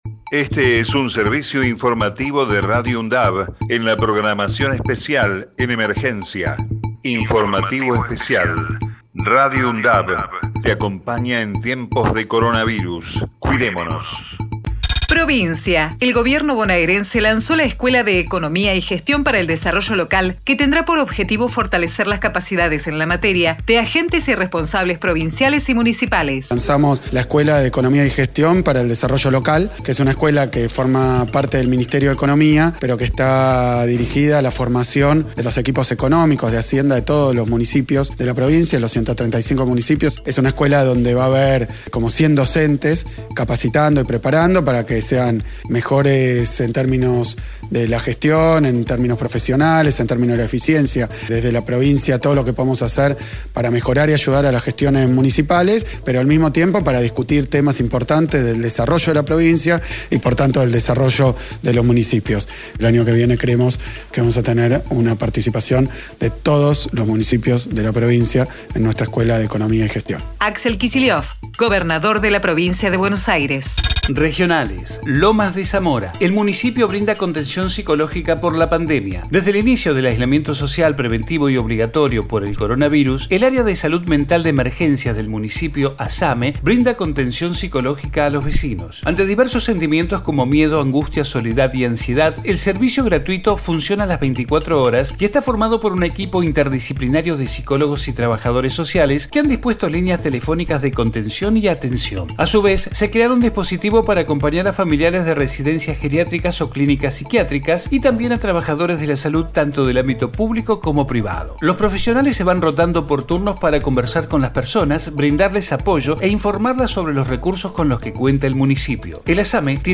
COVID-19 Informativo en emergencia 14 de octubre 2020 Texto de la nota: Este es un servicio informativo de Radio UNDAV en la programación especial en emergencia.